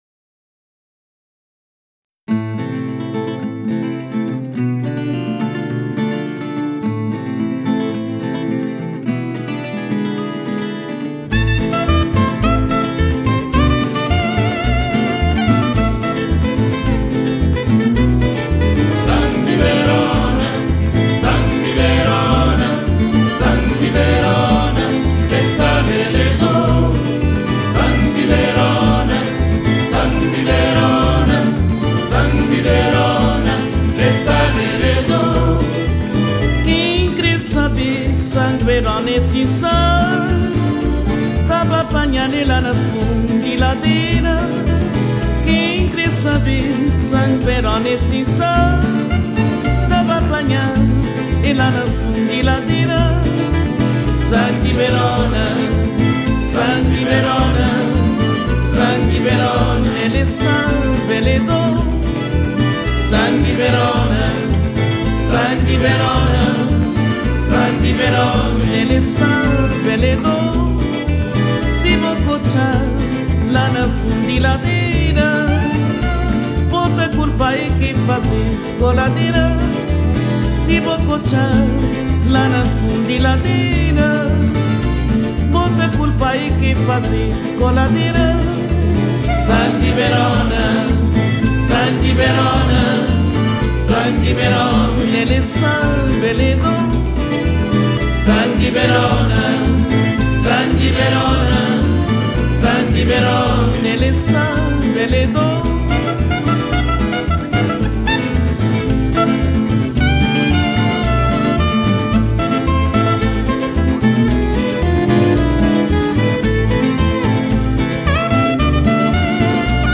Под музыку, напоминающую смесь шансонных традиций и босановы